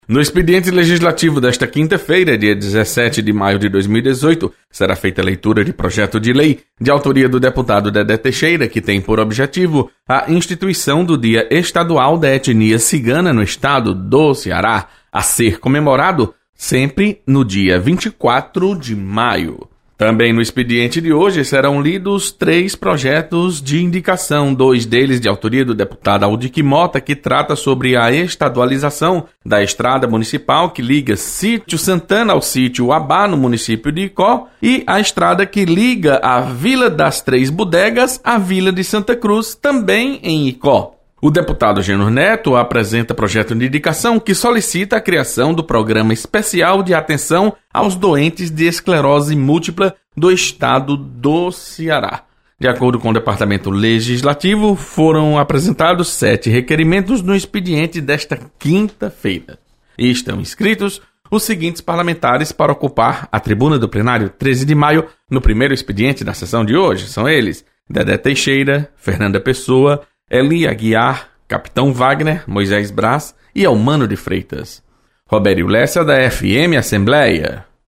Acompanhe as informações do expediente legislativo desta quinta-feira. Repórter